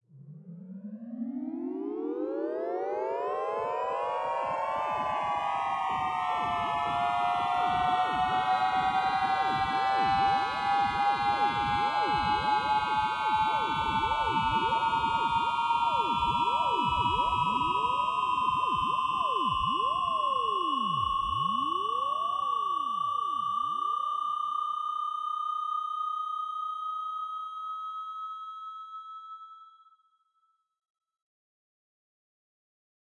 描述：极度分裂的滑音场，最终集中在一个低音域的夸特声上，相当大的混响，嘈杂的副效果，主要是反应式波形
标签： 电子 滑奏 噪声 混响
声道立体声